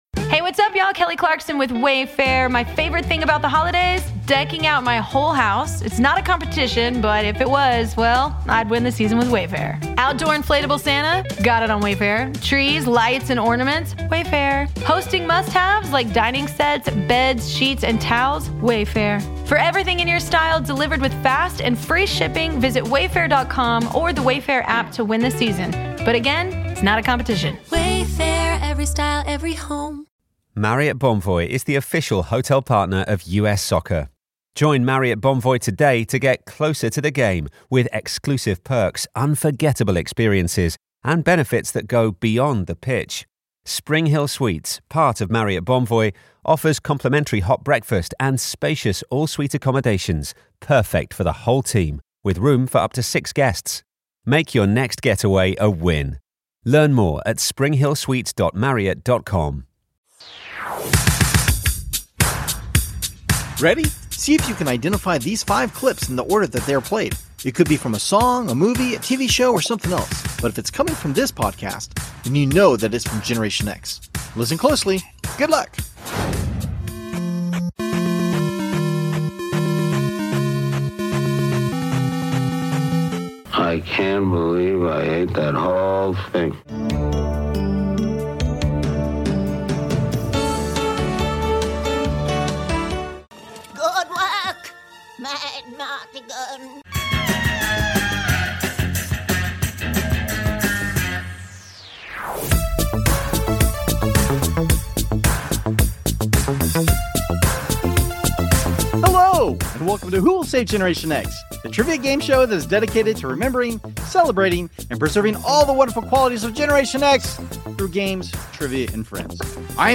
We welcome two first time guests who are brothers, to bring plenty of laughs as we play games and reminisce about our pop culture during Generation X. We hope it's worth the wait and an episode that you'll especially like.